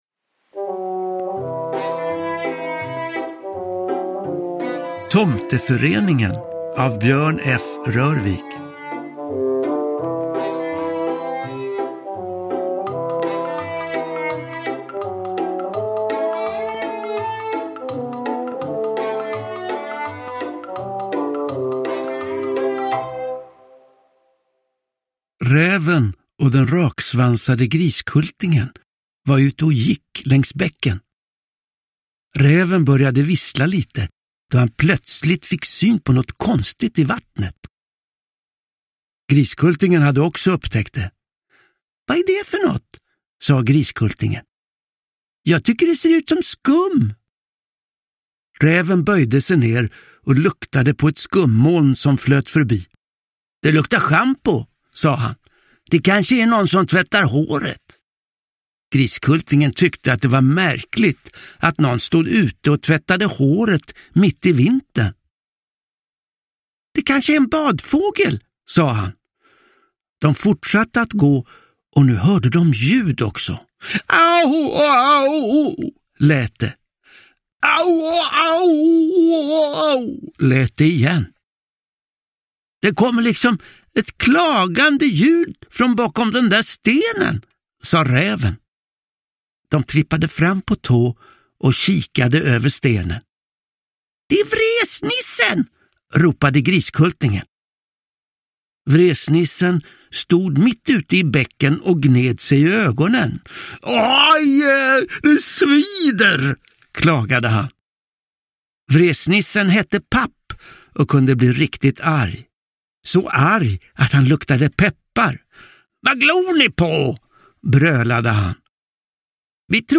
Räven och Griskultingen: Tomteföreningen – Ljudbok – Laddas ner
Uppläsare: Johan Ulveson